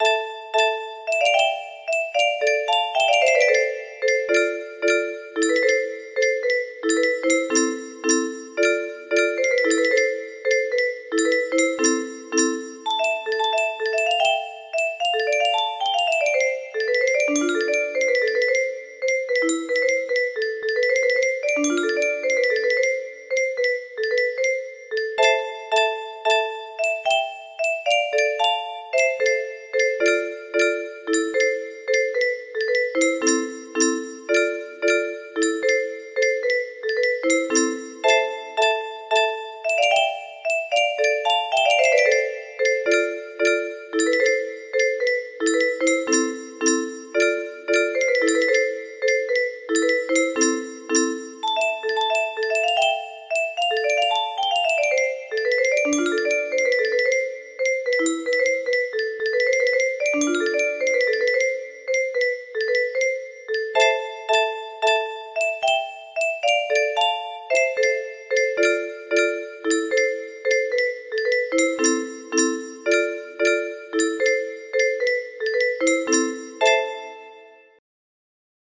Clone of Russian Folk sound - Oh, little apple (yablochko) music box melody
Wow! It seems like this melody can be played offline on a 15 note paper strip music box!